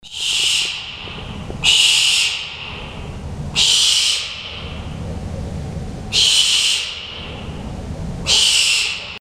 American Barn Owl (Tyto furcata)
Life Stage: Adult
Location or protected area: Reserva Natural del Pilar
Condition: Wild
Certainty: Observed, Recorded vocal